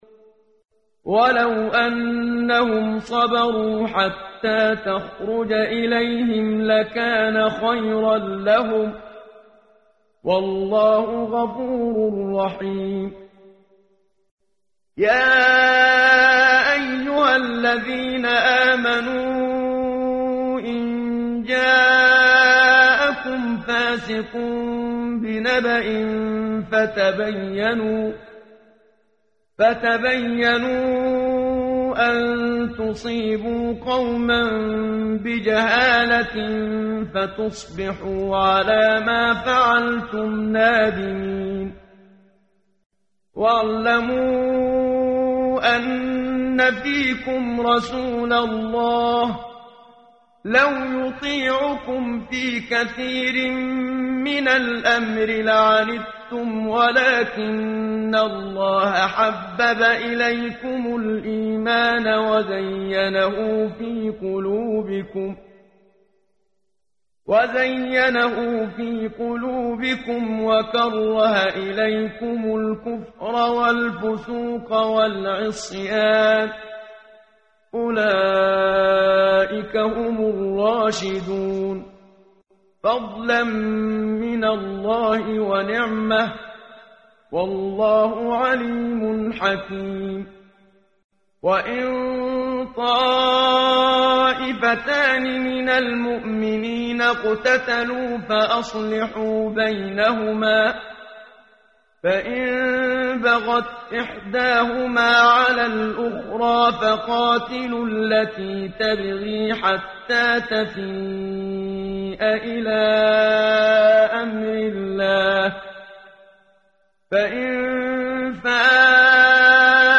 قرائت قرآن کریم ، صفحه 516 ، سوره مبارکه « الحجرات» آیه 5 تا 11 با صدای استاد صدیق منشاوی.